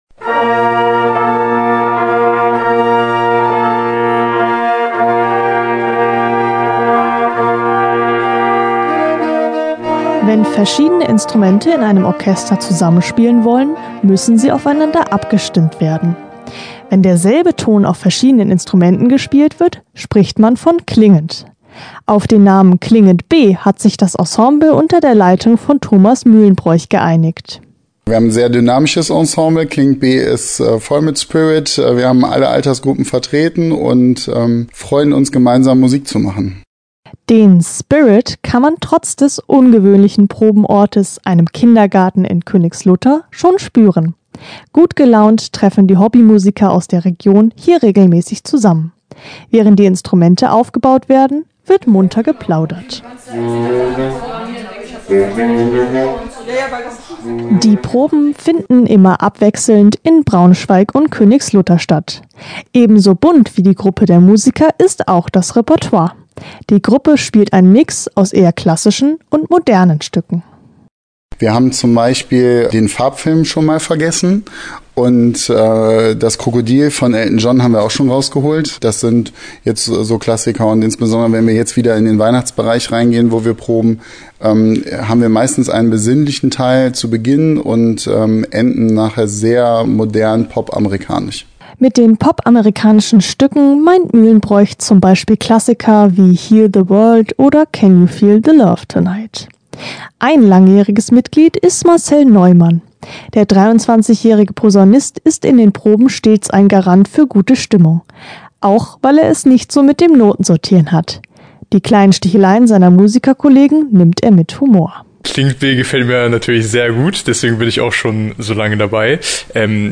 In Braunschweig und Königslutter probt das Bläserensemble „Klingend b“ fleißig. Denn in der Adventszeit stehen wieder einige Konzerte auf dem Programm.